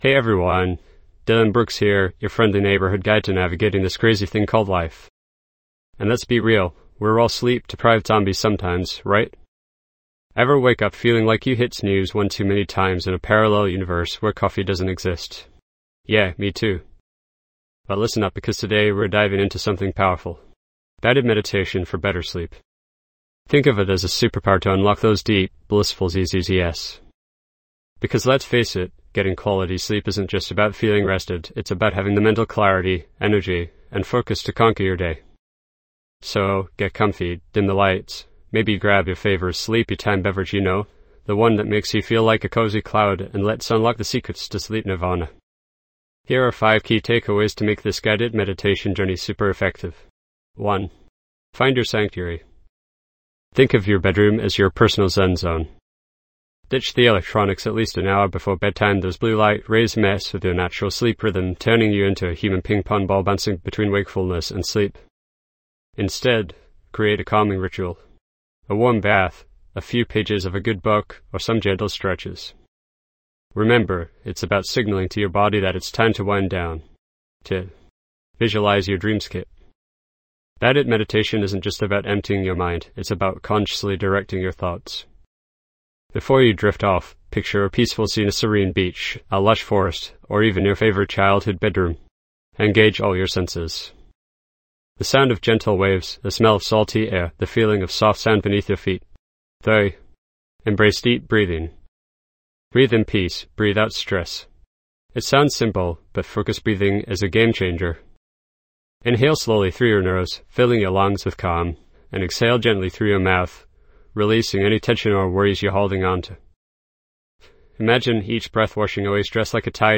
Deep Sleep Guided Meditation for Better Rest
This podcast is created with the help of advanced AI to deliver thoughtful affirmations and positive messages just for you.